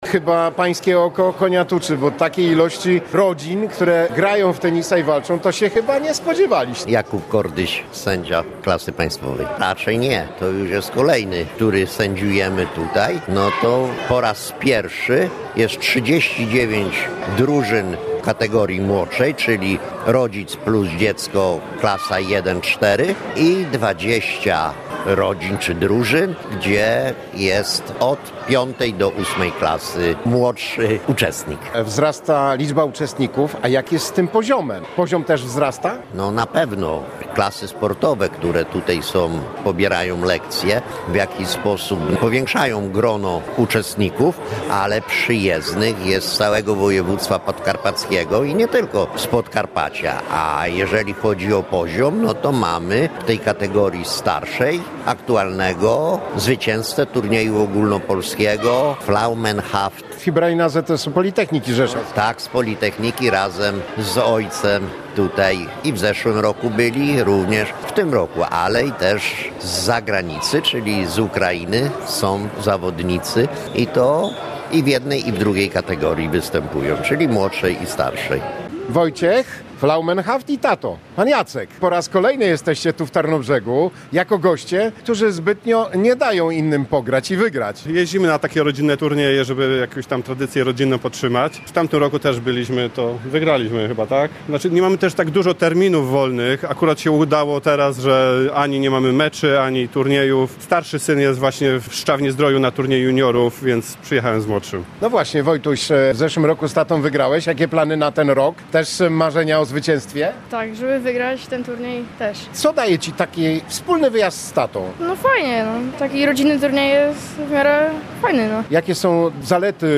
Dziś (7.12) w Tarnobrzegu odbył się wyjątkowy Turniej tenisa stołowego i chociaż przy stolach nie pojawiły się jedne z najlepszych zawodniczek świata – na co dzień grające w KST Enea Siarkopolu Tarnobrzeg, hala MOSiR tętniła życiem.